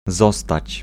Ääntäminen
IPA: /ˈzɔstat͡ɕ/